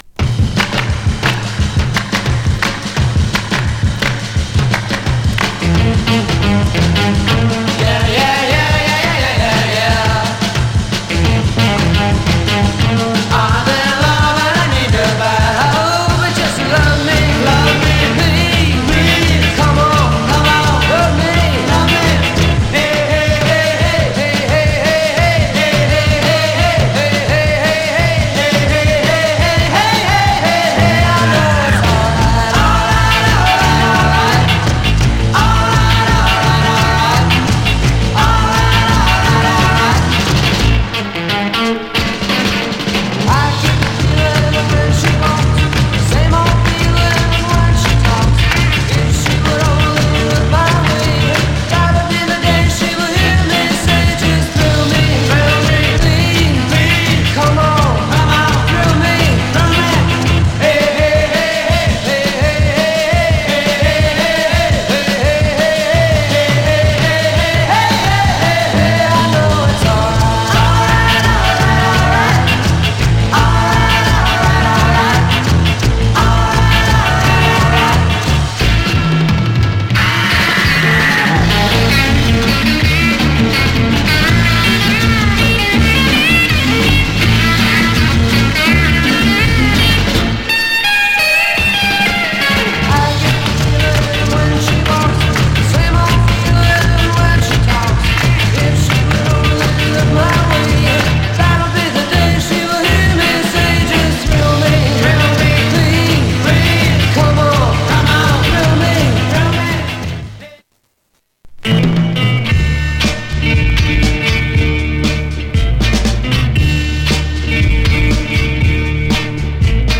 60's Garage